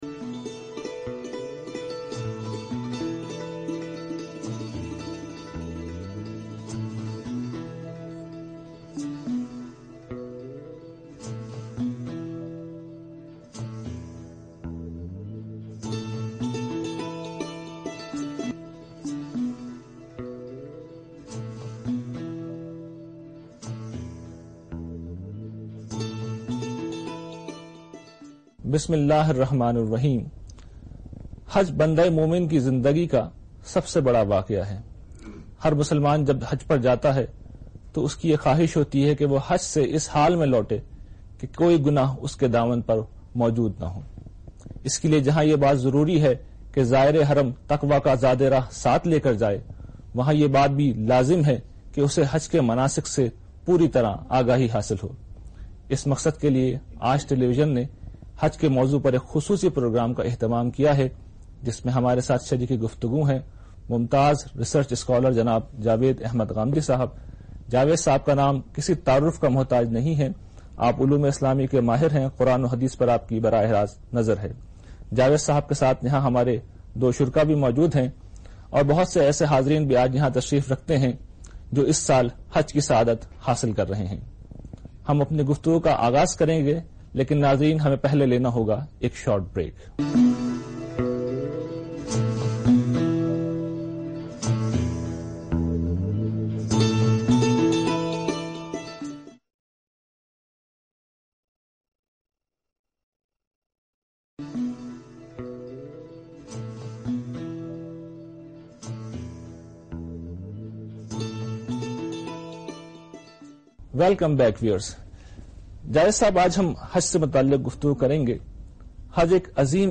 The program was aired on Aaj TV (in Pakistan).